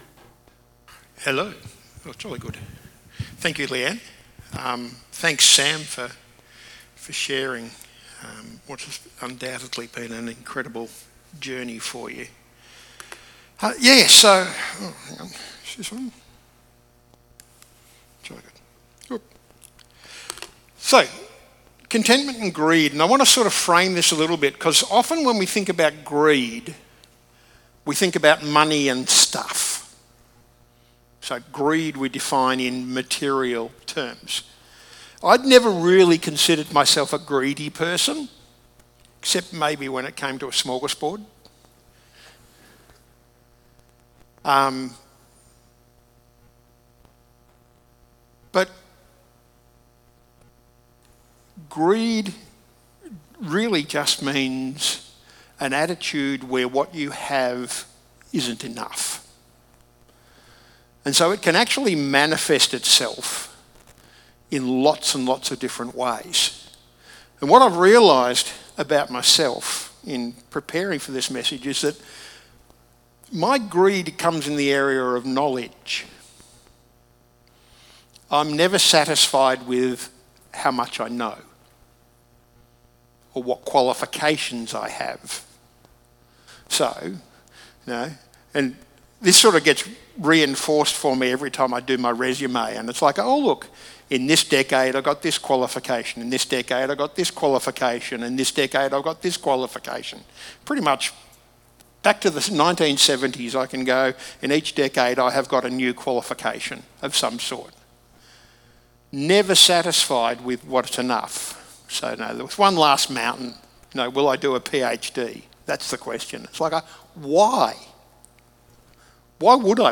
Sermons | Discover Church